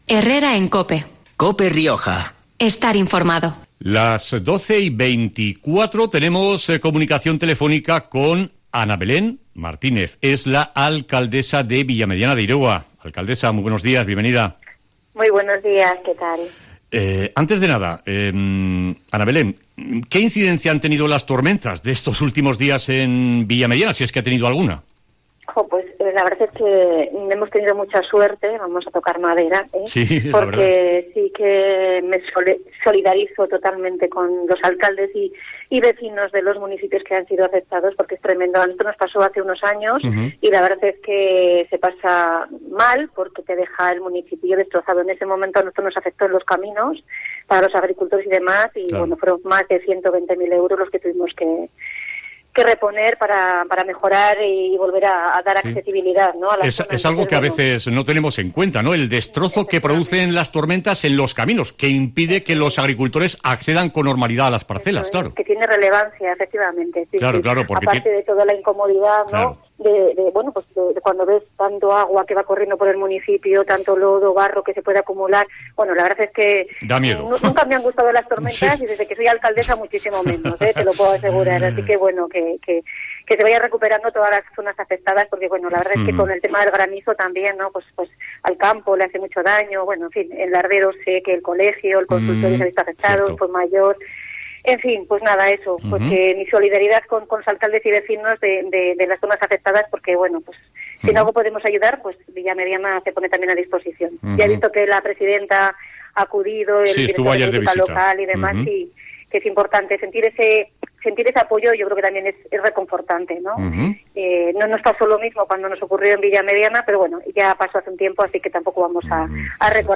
Entrevista en COPE Rioja a Ana Belén Martínez, alcaldesa de Villamediana